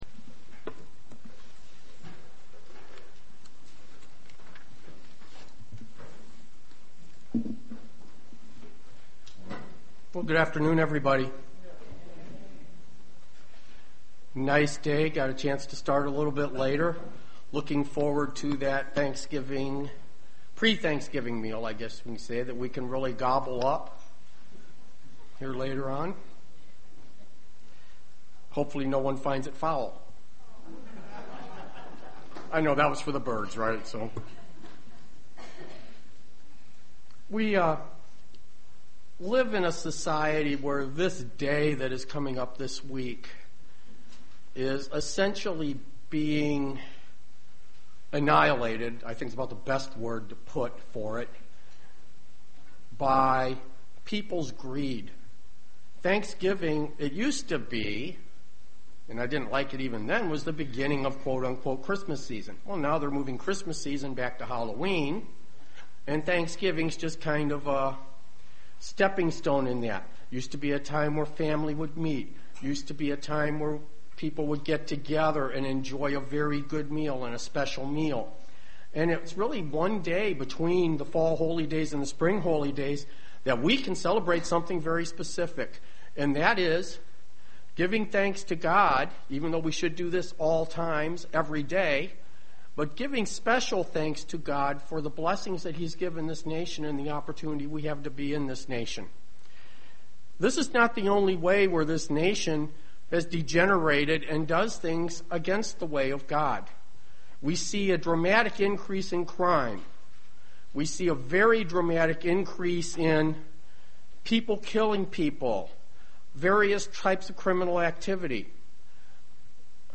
Print Listen to the parallelism between the cities of refuge and Jesus Christ UCG Sermon Studying the bible?
Given in Dayton, OH